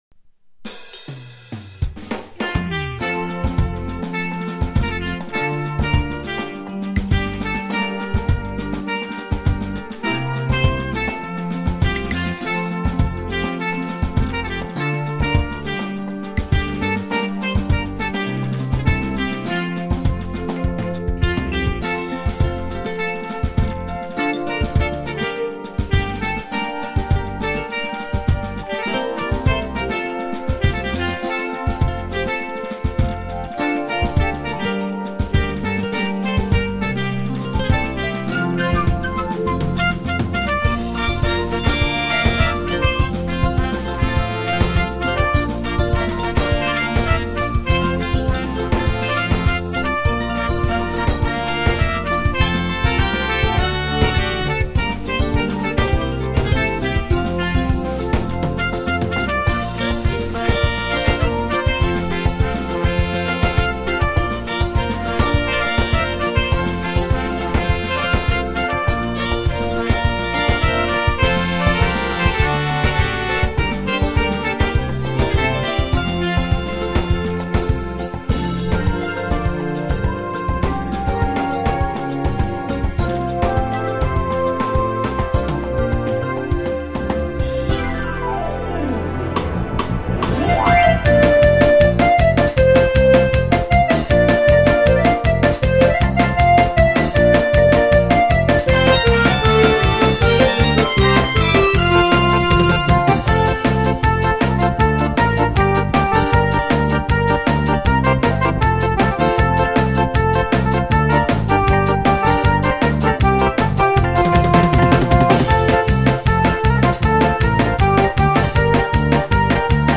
המנגינה המוכרת בביצוע קצת שונה.
הקטע הזה מהיר סווחף!